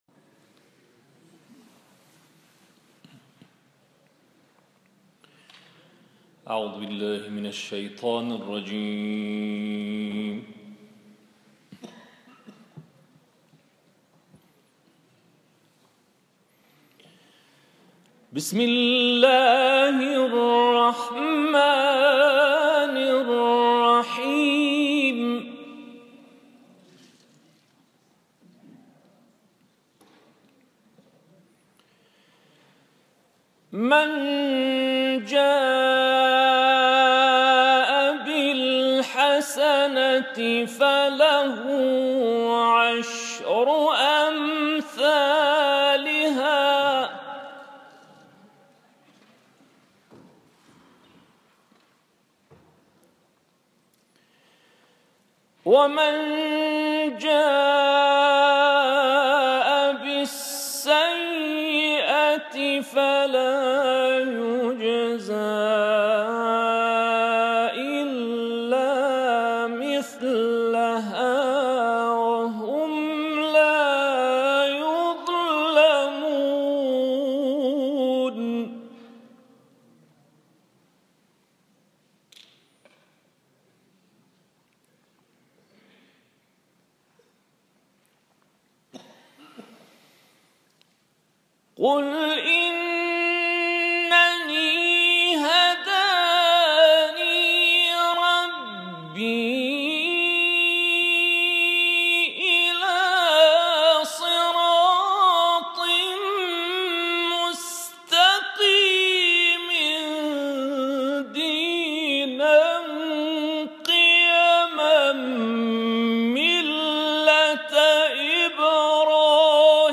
این تلاوت کوتاه روز گذشته اجرا شده است و مدت زمان آن 6 دقیقه است.